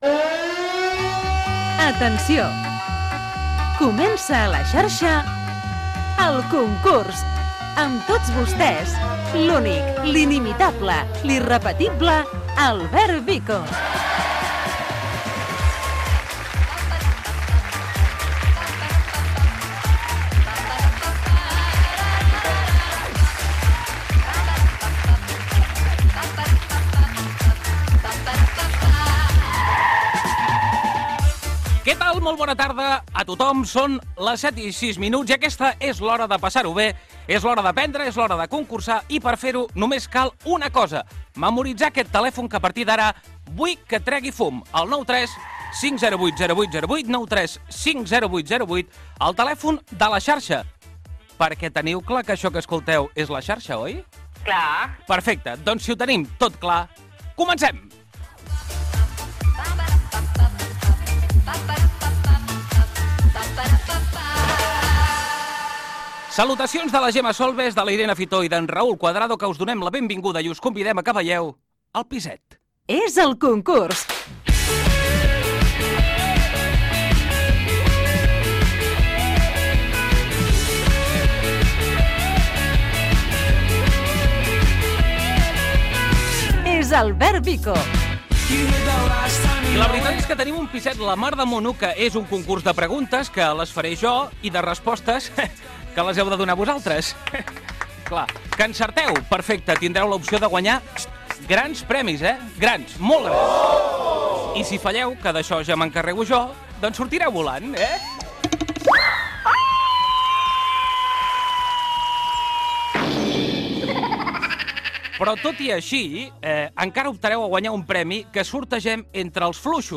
Careta, hora, presentació del programa, telèfon, indicatiu, trucada a Linyola, premis, telèfon de participació i trucades dels oients.